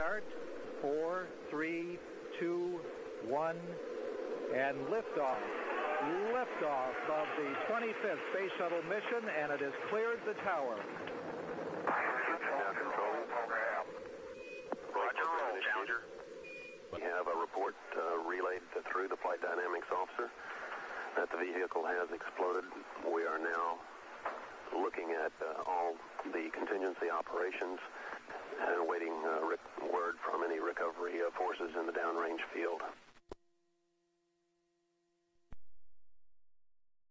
Hear the news of Challenger Accident.